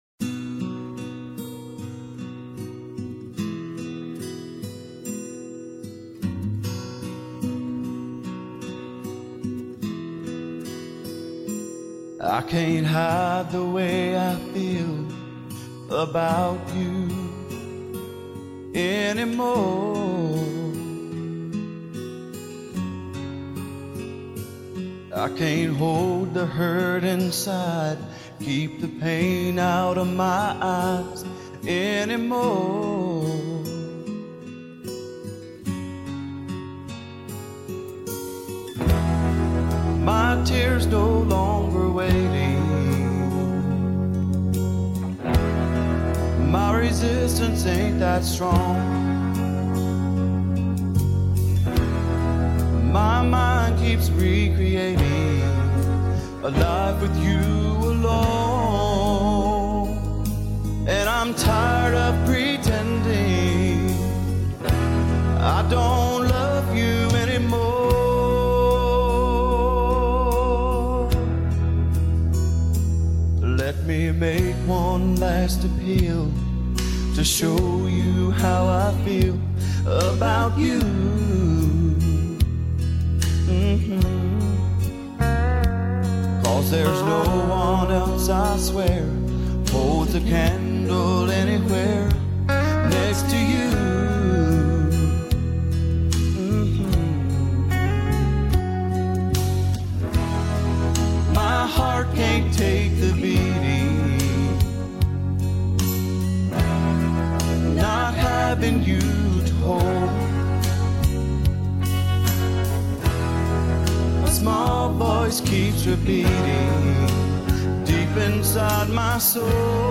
solo country song